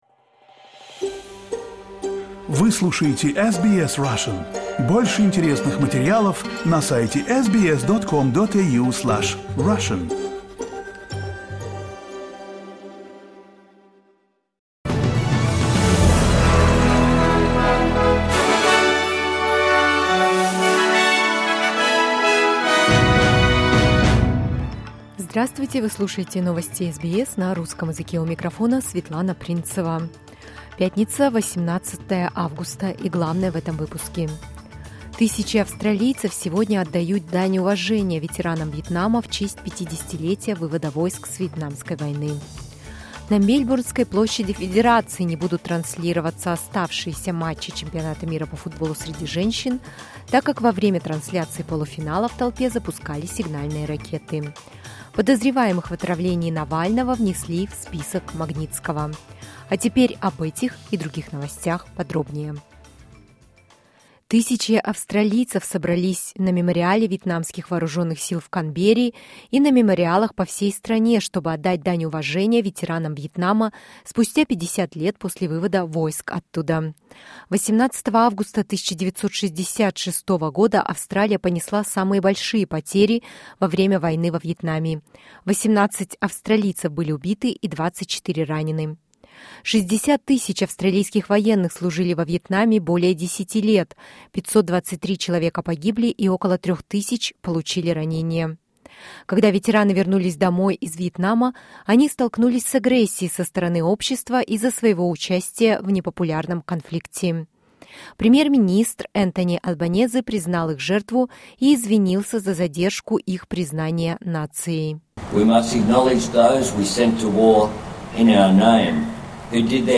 SBS news in Russian — 18.08.2023